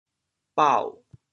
潮州 bao6 文
bao6.mp3